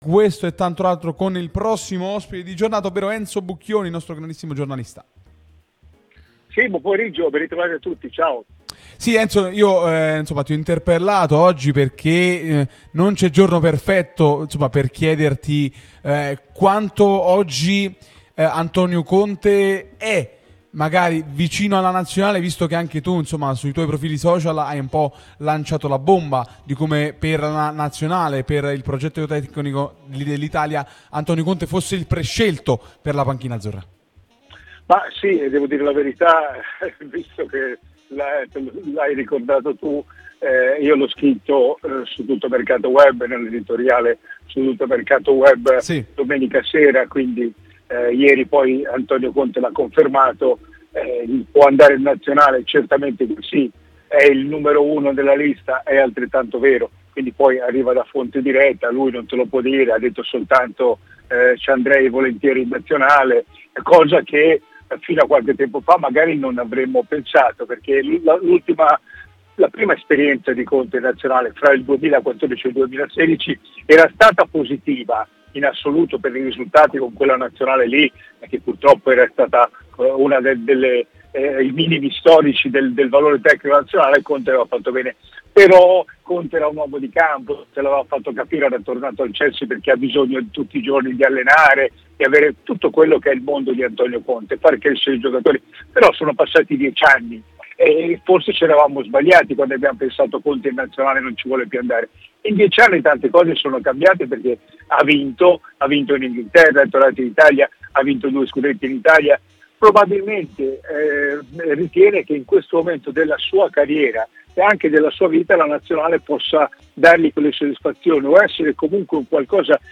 Radio Tutto Napoli , l'unica radio tutta azzurra e live tutto il giorno